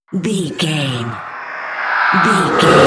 Creature riser horror
Sound Effects
In-crescendo
Atonal
scary
ominous
haunting
eerie
roar